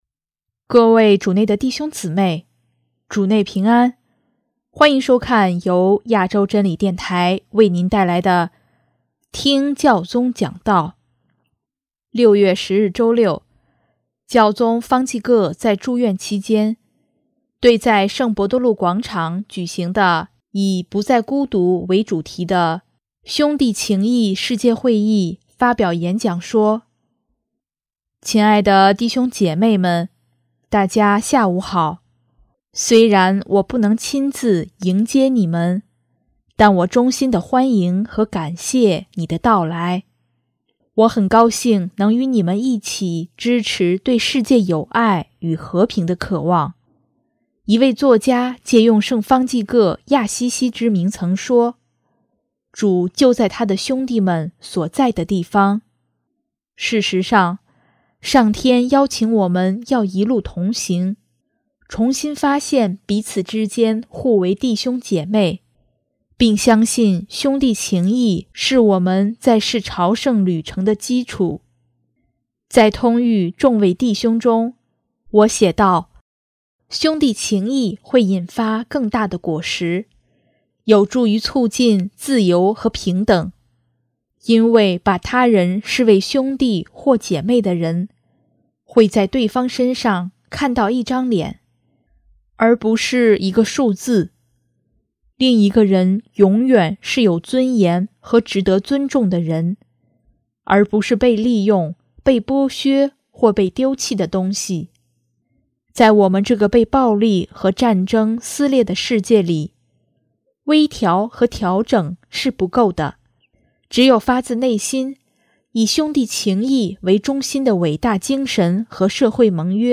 6月10日周六，教宗方济各在住院期间，对在圣伯多禄广场举行的以“不再孤独”为主题的兄弟情谊世界会议（World Meeting on Human Fraternity“Not alone”）发表演讲说：